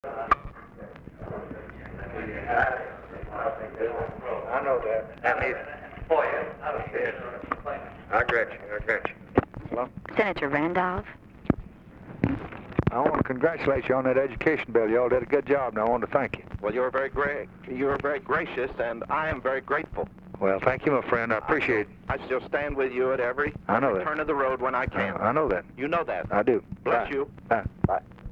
Conversation with JENNINGS RANDOLPH, December 10, 1963